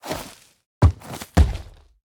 Minecraft Version Minecraft Version snapshot Latest Release | Latest Snapshot snapshot / assets / minecraft / sounds / mob / sniffer / digging_stop1.ogg Compare With Compare With Latest Release | Latest Snapshot
digging_stop1.ogg